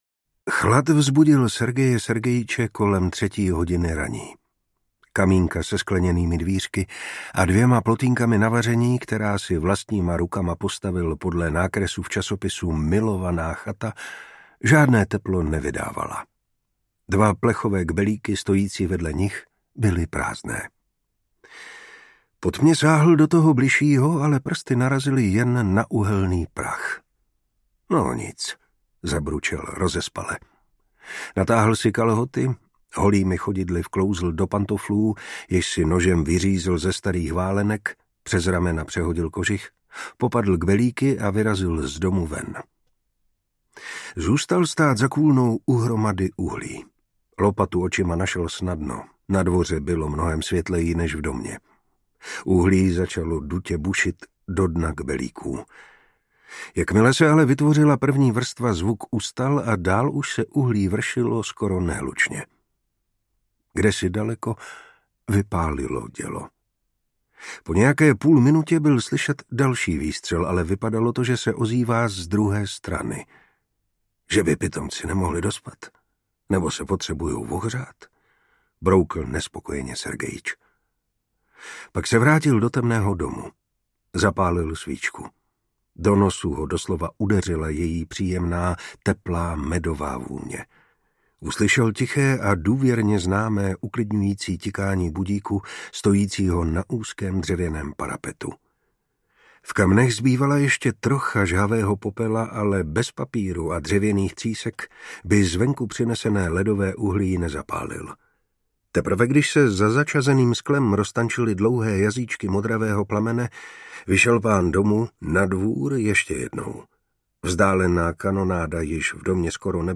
Šedé včely audiokniha
Ukázka z knihy
• InterpretMartin Preiss